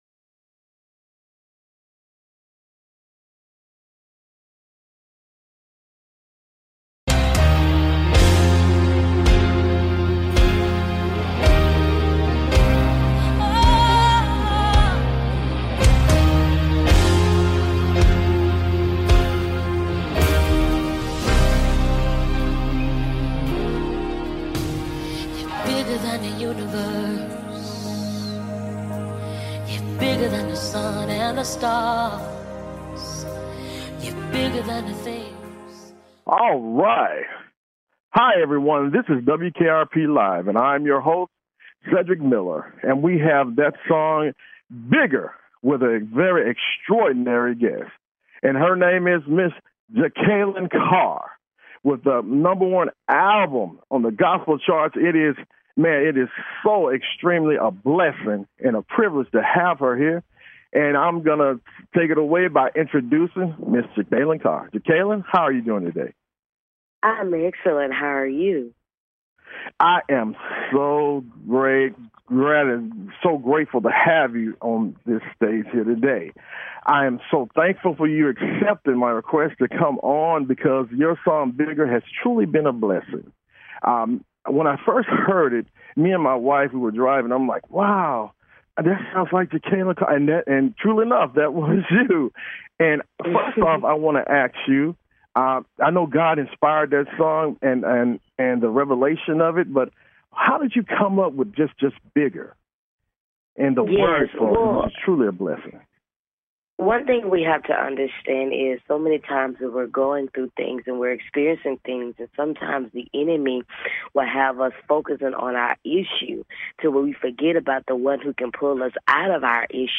Talk Show Episode
Guests, Jekalyn Carr